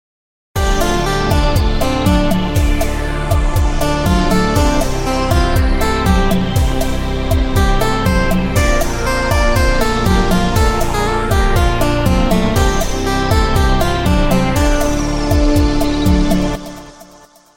A relaxing, short theme.